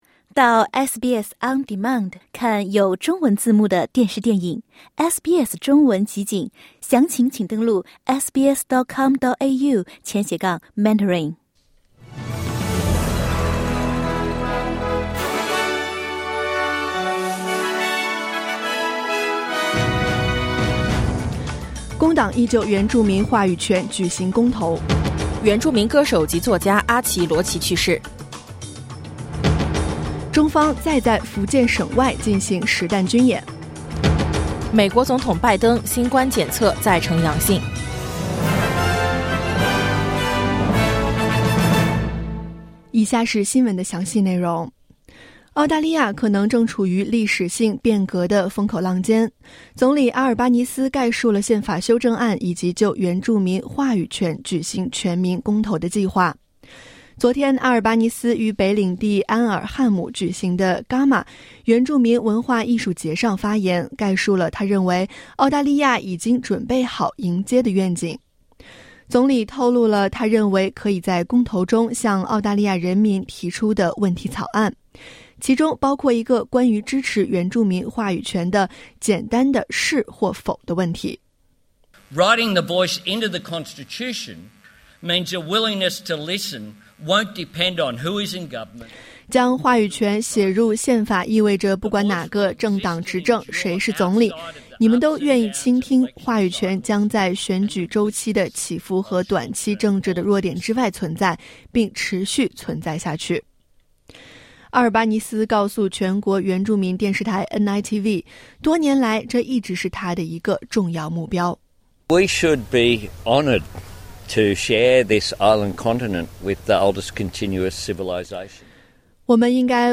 SBS早新聞（2022年7月31日)